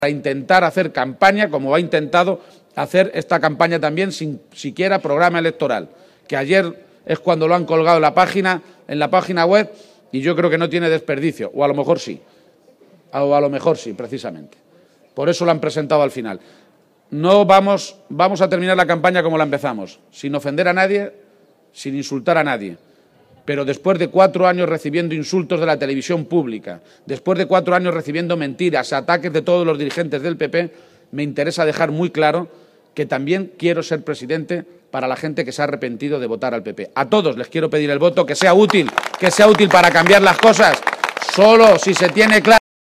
En el acto público del PSOE en Caudete, también anunció que si el próximo 31 de Mayo, Día de Castilla-La Mancha, es presidente de la Comunidad Autónoma,- gracias al voto mayoritario de la ciudadanía-, su discurso será “incluyente”, remarcando que la próxima Legislatura representará “la esperanza de una Región que si ha sido capaz de llegar tan lejos en la historia, partiendo de una posición tan atrasada, podremos sobreponernos al bache que ha supuesto Cospedal”.